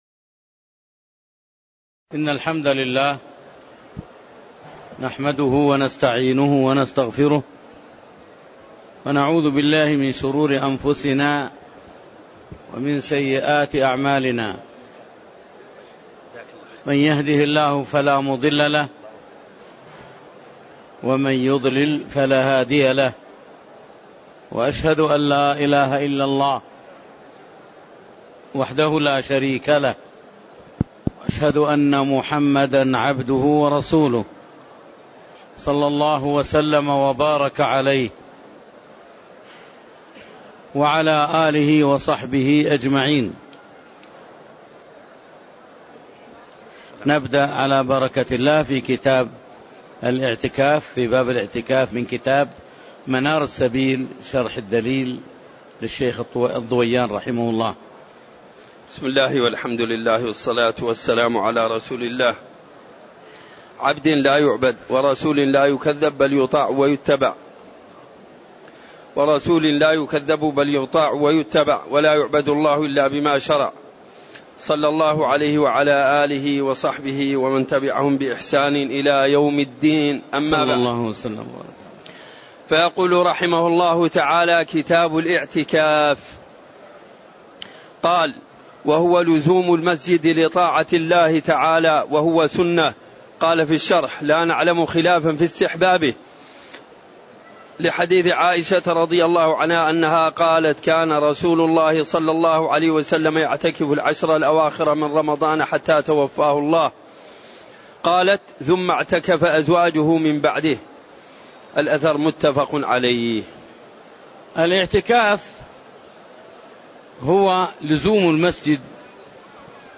تاريخ النشر ١٦ رمضان ١٤٣٨ هـ المكان: المسجد النبوي الشيخ